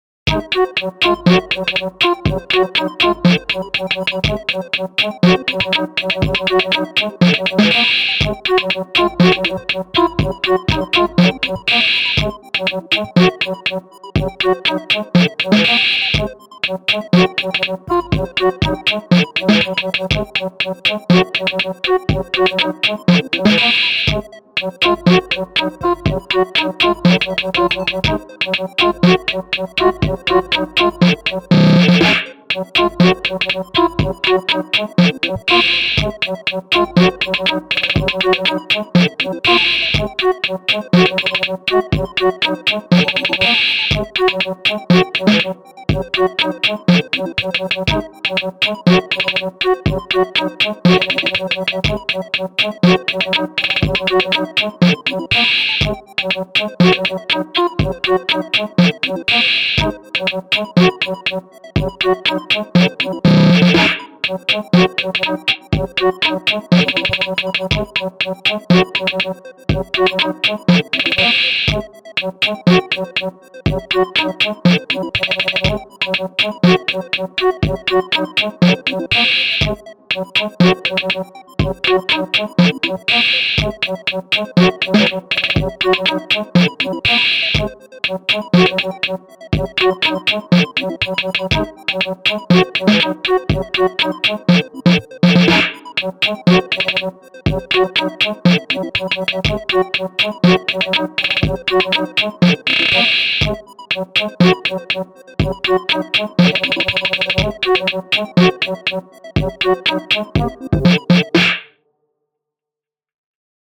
フリーBGM
劇伴・SE